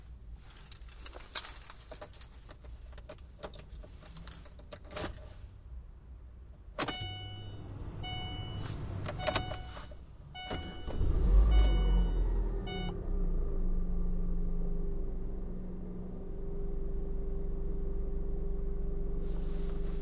随机的" auto Prius ext 发动机拉起怠速停止 长时间关机 启动拉走 缓慢的各种碎片
描述：汽车普锐斯ext发动机拉起怠速停止长时间关闭开始拉开慢速各种件
标签： 普锐斯 空闲 分机 启动 自动 关闭 关机 发动机
声道立体声